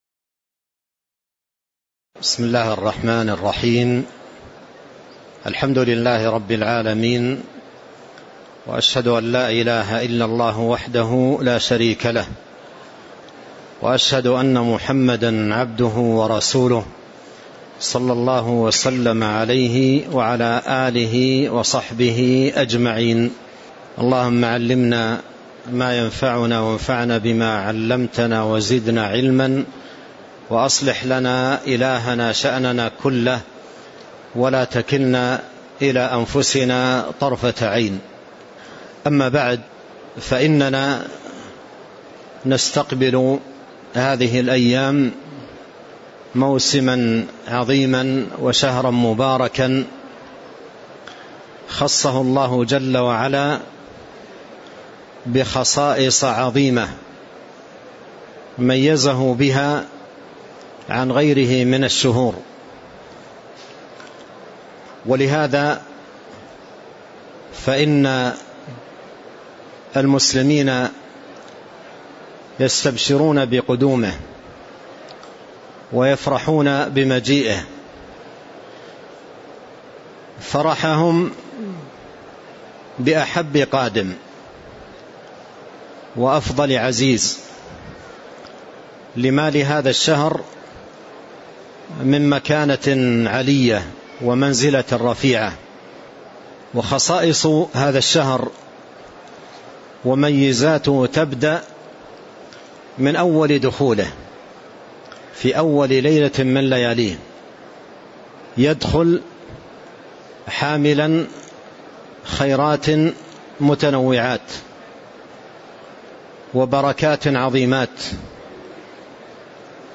تاريخ النشر ٢٨ رمضان ١٤٤٤ هـ المكان: المسجد النبوي الشيخ: فضيلة الشيخ عبد الرزاق بن عبد المحسن البدر فضيلة الشيخ عبد الرزاق بن عبد المحسن البدر كيف نستقبل رمضان